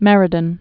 (mĕrĭ-dən)